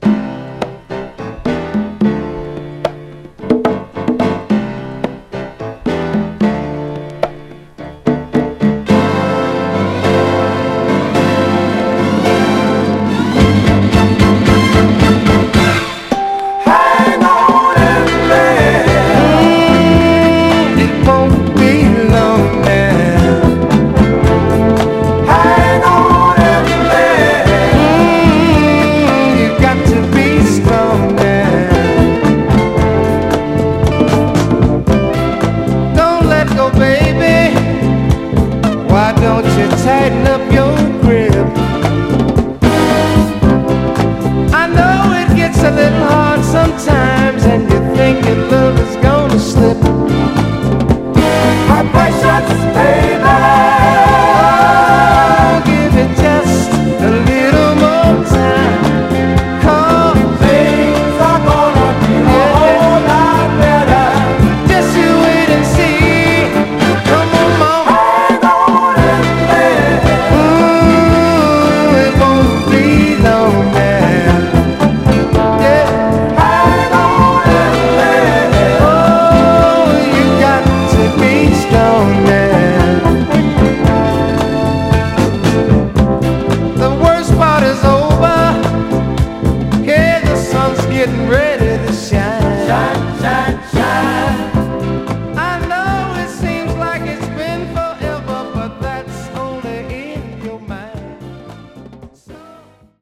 メロウなピアノとパーカッションのイントロから最高な、フィリー・ソウルらしい温かみのある美しいミッド・ソウルです！
※試聴音源は実際にお送りする商品から録音したものです※